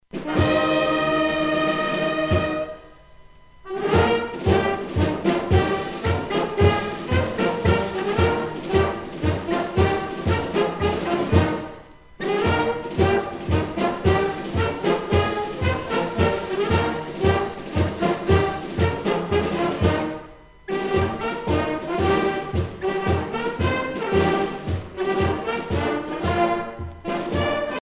à marcher
Pièce musicale éditée